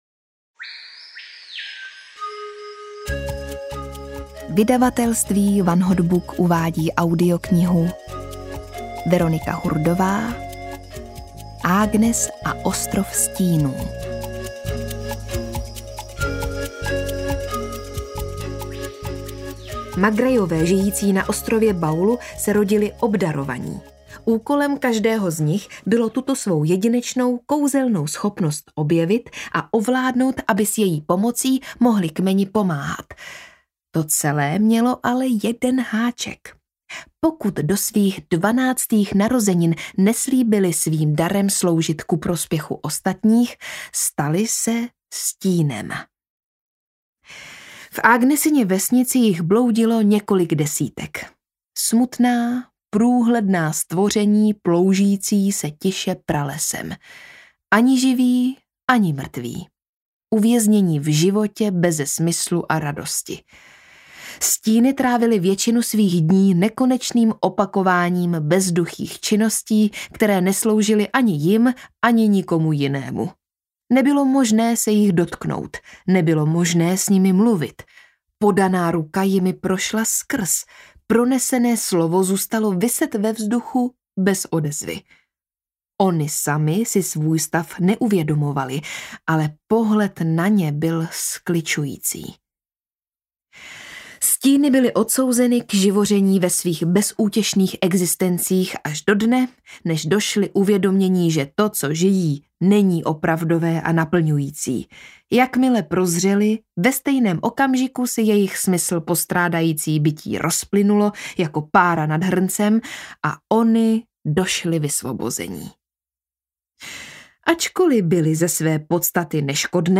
Agnes a ostrov Stínů audiokniha
Ukázka z knihy
• InterpretVeronika Khek Kubařová, Hynek Čermák
agnes-a-ostrov-stinu-audiokniha